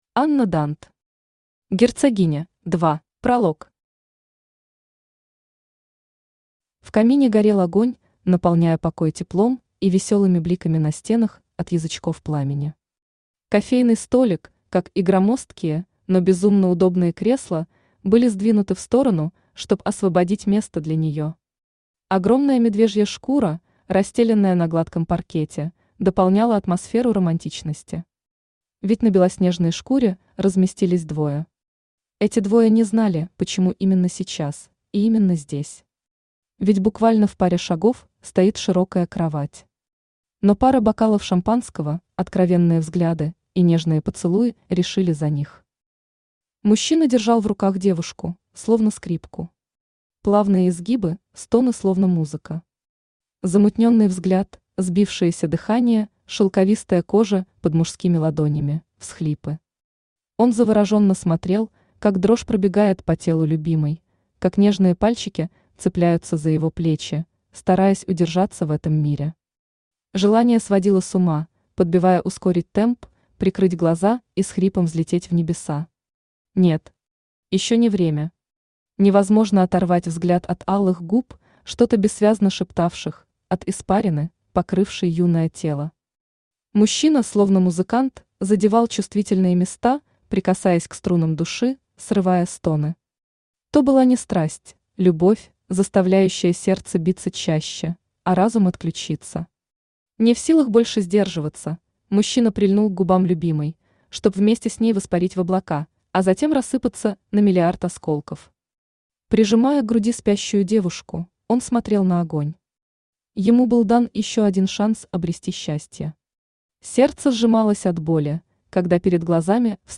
Аудиокнига Герцогиня – 2 | Библиотека аудиокниг